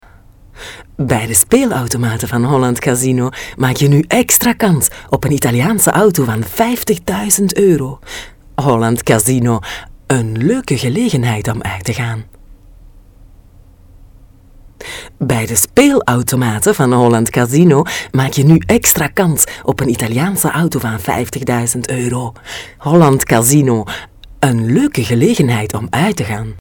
Sprecherin niederländisch aus Belgien, Weitere Sprachen: französisch und spanisch.
Sprechprobe: eLearning (Muttersprache):
dutch female voice over artist. experienced voice from Belgium, also French and Spanish possible. commercials, GPS,